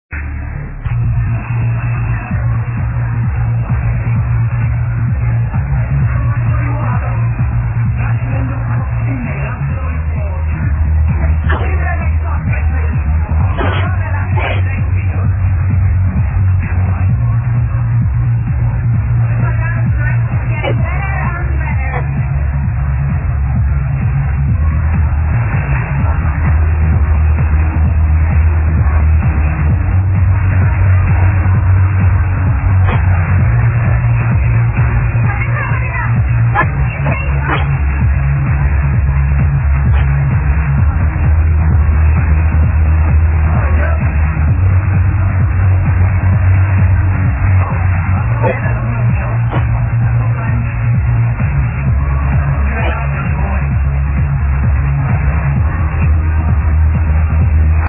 PS. sorry for the crap quality, its taken off a TV program